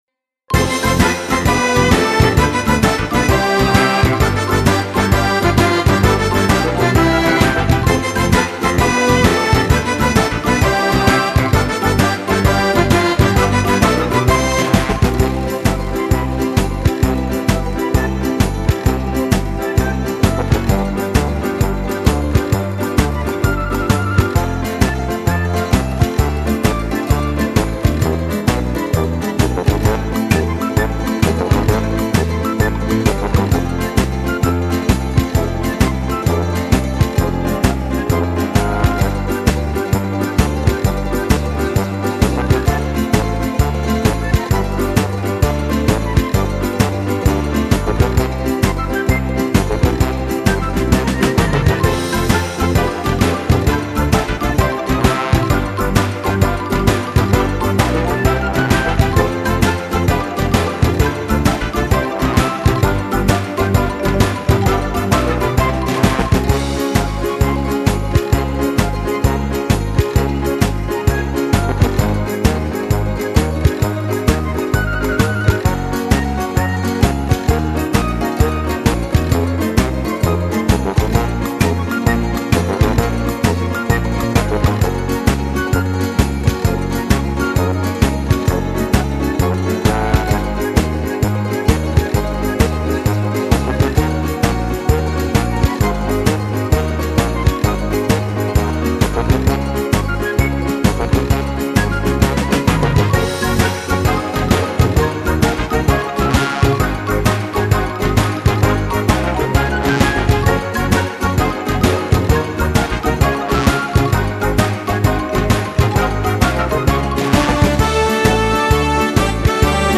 Genere: Tarantella
Scarica la Base Mp3 (3,94 MB)